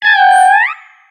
Cri de Tutafeh dans Pokémon X et Y.